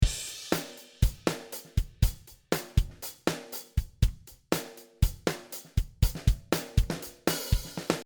荒々しいロックなどに向いた、リムの響きを強調した音
ローファイ
ドラム全体
この音のポイントは、中低域である550Hzあたりを強調し、リムの響きである『コォン』という部分を前面に出している事です。